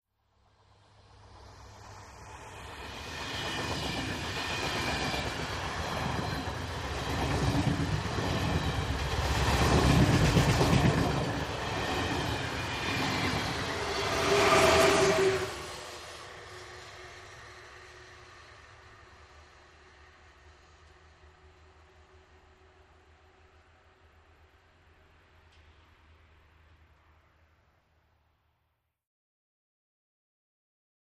Electric Train ( European ), By Very Fast, Cu.